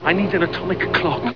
All sounds are of Paul McGann from the telefilm, Doctor Who.
Sounds were originally sampled at 22 kHz, 16-bit mono with GoldWave, then resampled to 11 kHz, 8-bit mono to reduce their file size.